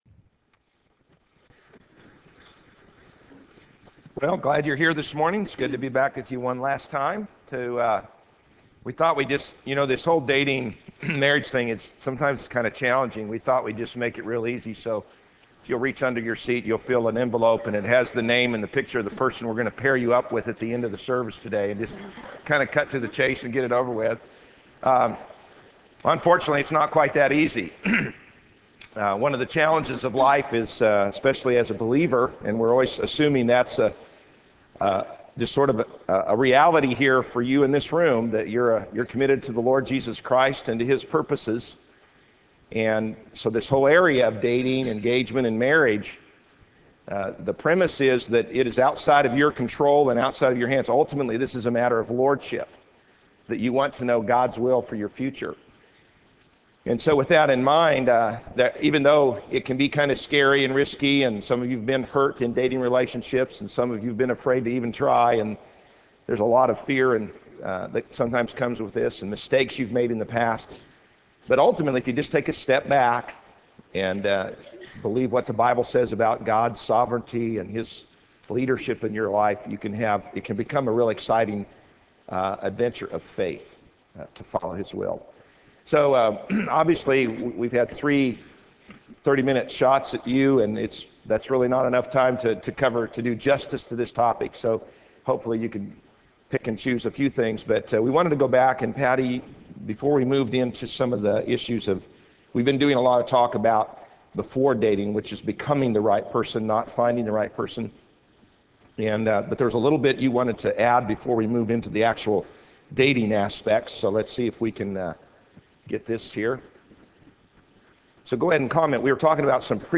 Crabtree Family Life Series Chapel